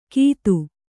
♪ kītu